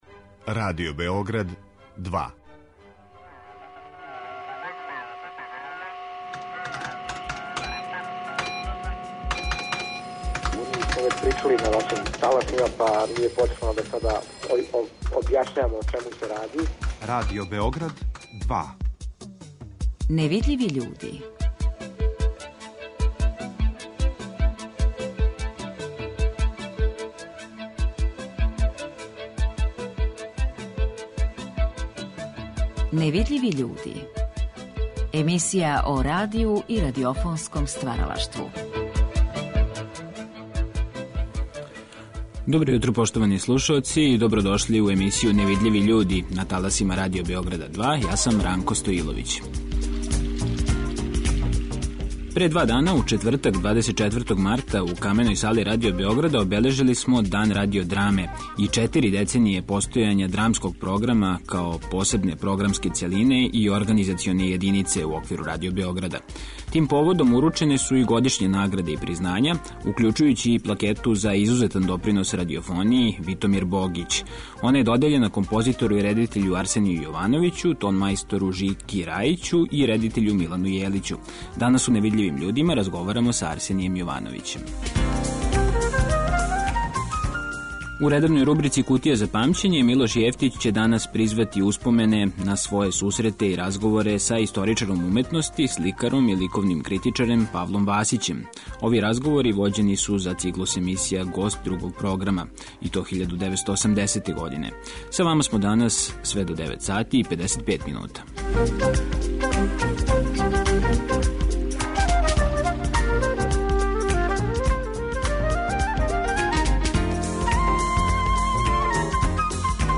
Емисија о радију и радиофонском стваралаштву.